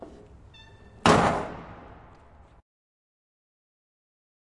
废弃的工厂 金属后世界末日的回声 " 砰砰的回声 2b
描述：记录在都柏林的一家废弃工厂。
Tag: 工业 工厂 金属 崩溃 噪声